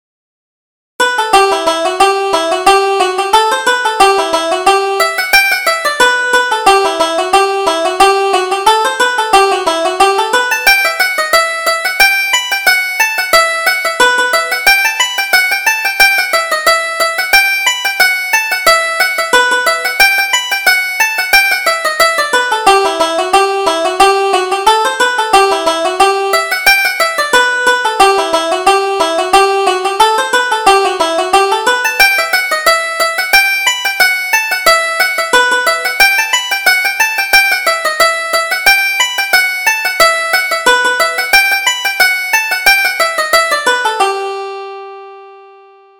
Reel: The Field of Oats